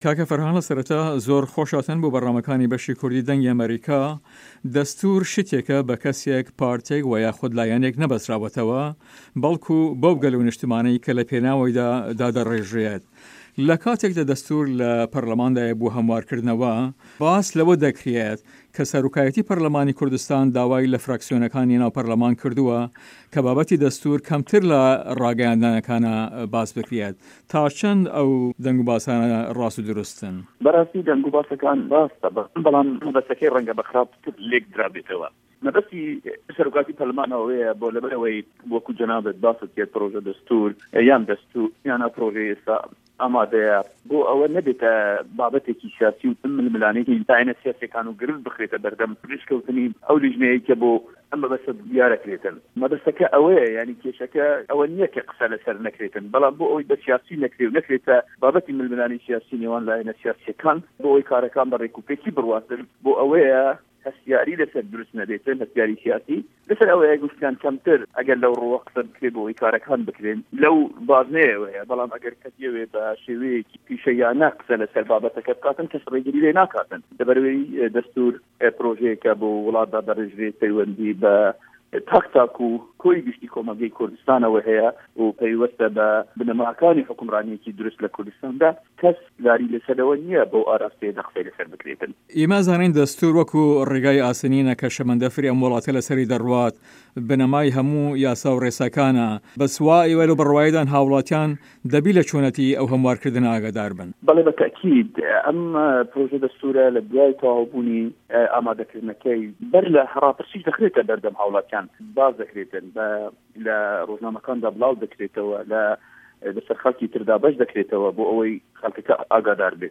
فه‌رحان جه‌وهه‌ر ئه‌ندام په‌رله‌مانی هه‌رێمی کوردستان له‌ سه‌ر لیستی پارتی دیموکراتی کوردستان له‌ هه‌ڤپه‌ێڤینێکدا له‌گه‌ڵ به‌شی کوردی ده‌نگی ئه‌مه‌ریکا ده‌ڵێت" ئه‌وه‌ی ‌ بابه‌تی ده‌ستور که‌متر له‌ راگه‌یاندنه‌کاندا باس بکرێت راسته‌ به‌ڵام مه‌به‌سته‌که‌‌ی ره‌نگه‌ به‌ خراپ لێکدارابێته‌وه‌، مه‌به‌ستی سه‌رۆکایه‌تی په‌رله‌مان ئه‌وه‌یه‌ بۆ ئه‌وه‌ی بابه‌تی دستور وه‌ یاخود پرۆژی ده‌ستور نه‌بێته‌ بابه‌تێکی سیاسی و ململانی لایه‌نه‌ سیاسیه‌کان و گرفت بخرێته‌ به‌رده‌م ئه‌و لیژنه‌یه‌ی که‌ بۆ ئه‌م مه‌به‌سته‌ دیار ده‌کرێت، مه‌به‌سته‌که‌ ئه‌وه‌یه،‌ نه‌ک قسه‌ی له‌سه‌ر نه‌کرێت.